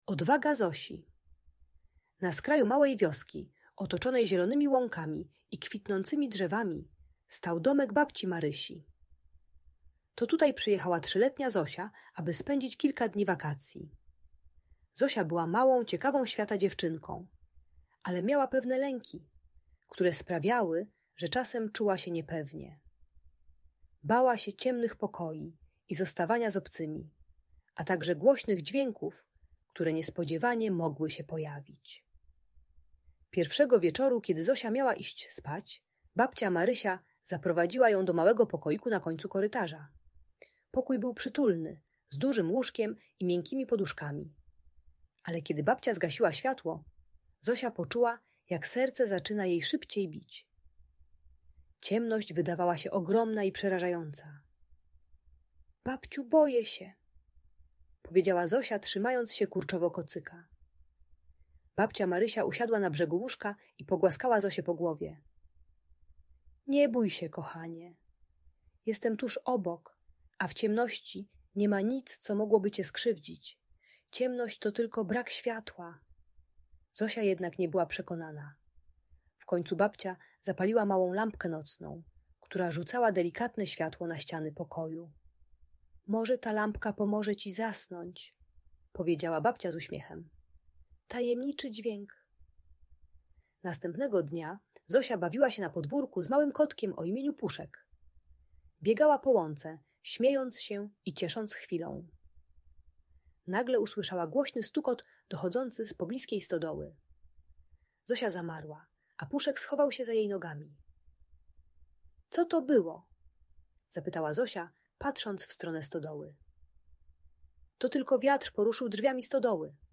Odwaga Zosi - Lęk wycofanie | Audiobajka